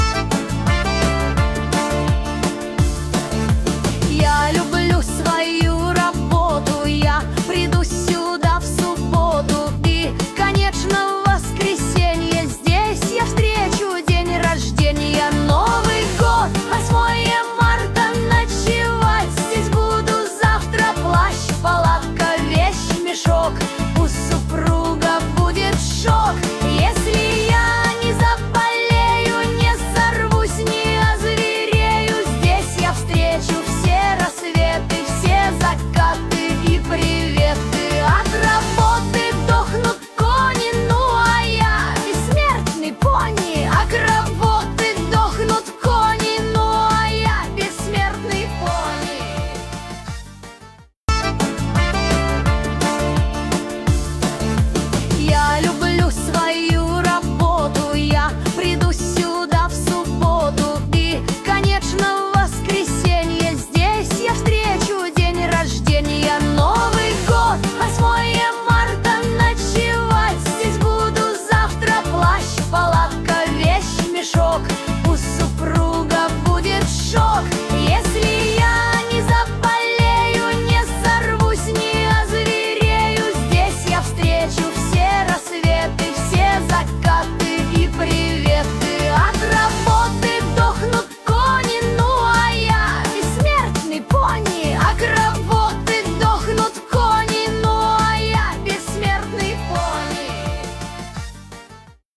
Нейросеть Песни 2025